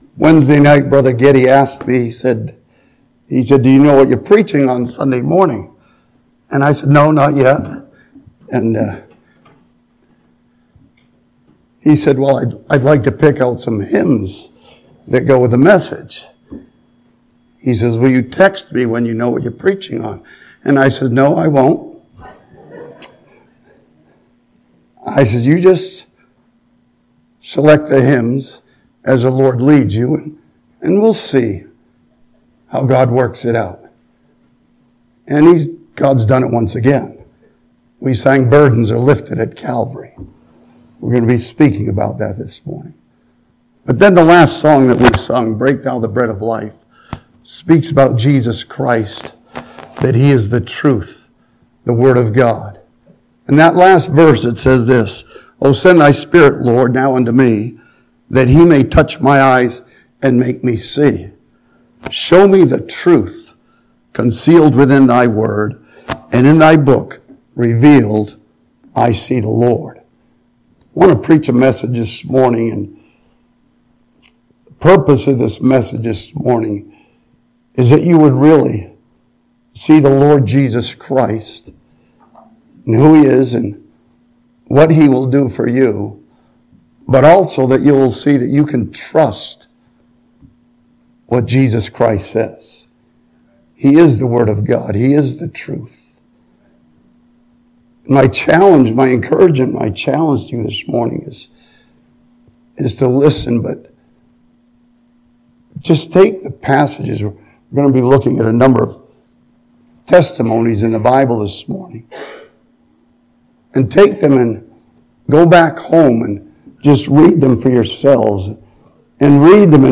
This sermon from Matthew chapter 11 reminds us that Jesus has called us to come unto Him and find rest.